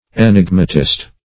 Search Result for " enigmatist" : The Collaborative International Dictionary of English v.0.48: Enigmatist \E*nig"ma*tist\, n. [Gr. ?.] One who makes, or talks in, enigmas.